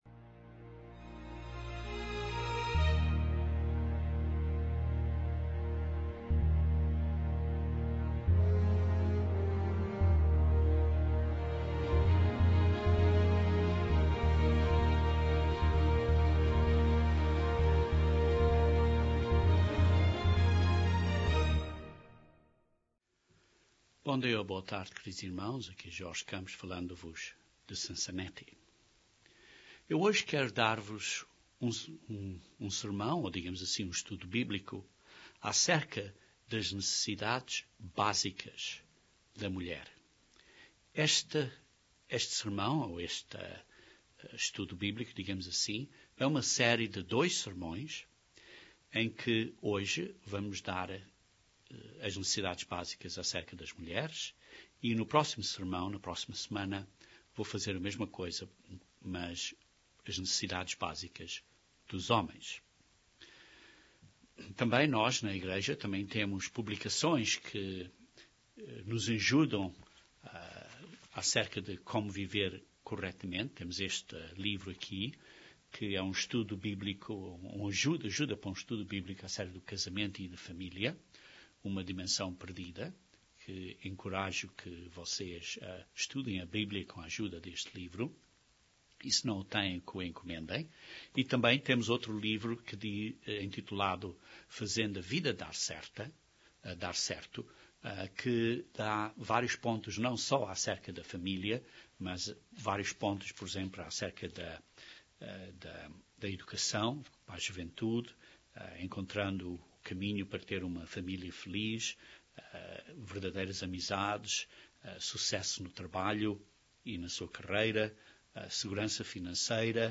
Este é o primeiro de dois sermões acerca das necessidades básicas do homem e da mulher. Este sermão cobre cinco necessidades da mulher.